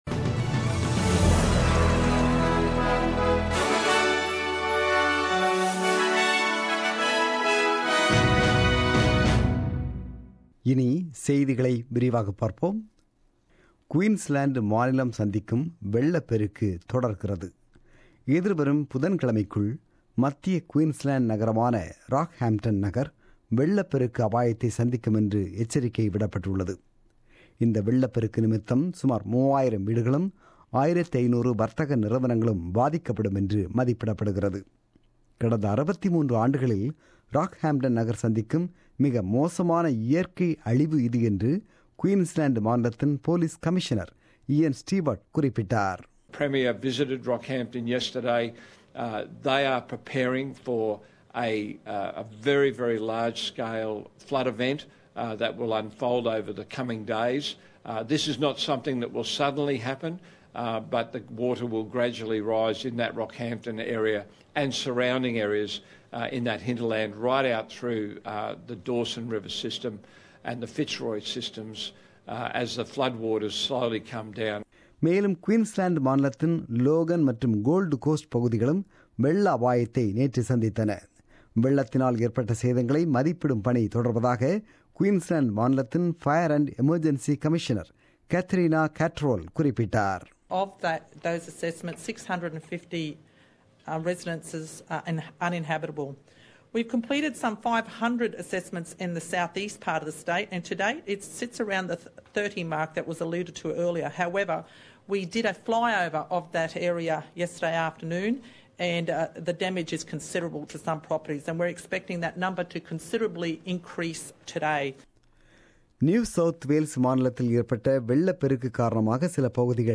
The news bulletin broadcasted on 2 April 2017 at 8pm.